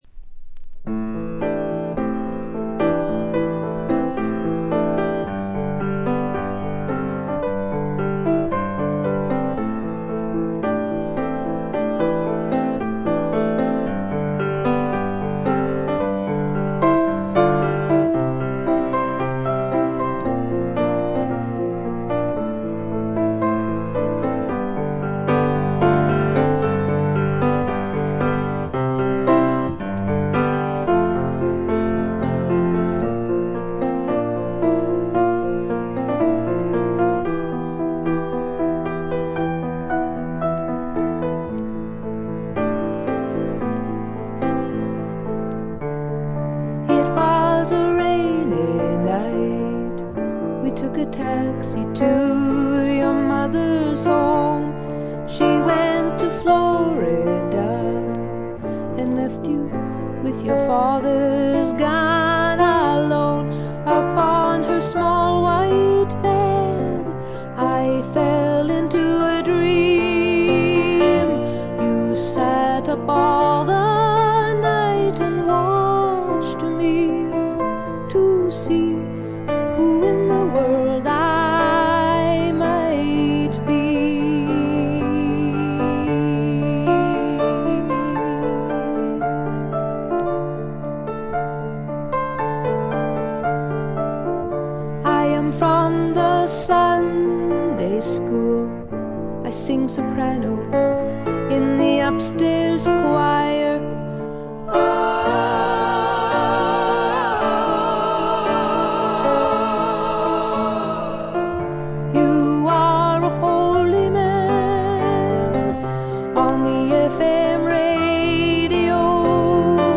試聴曲は現品からの取り込み音源です。
※B1-B2連続です。B1エンディングでプツ音。